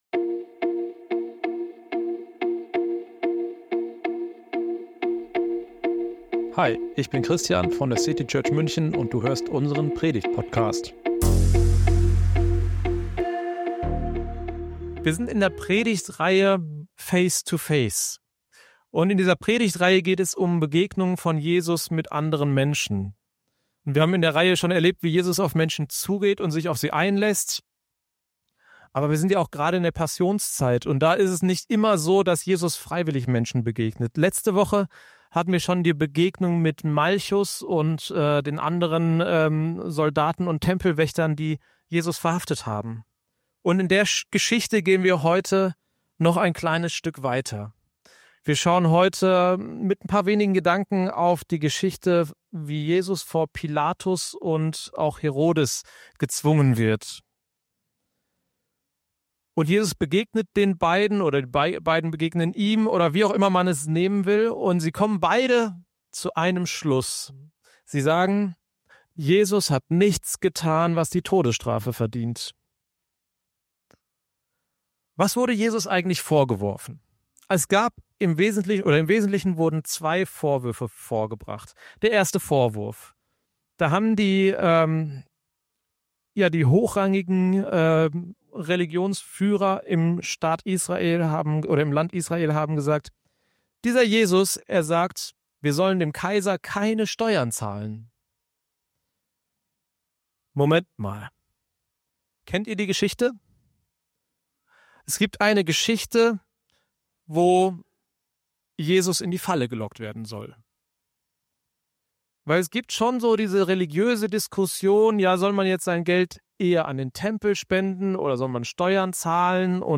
In diesem Input zum Abendmahlsgottesdienst schauen